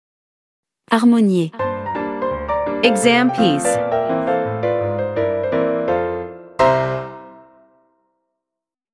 Sample MP3 | Bars : 37-40
Professional-level Piano Exam Practice Materials.
• Vocal metronome and beats counting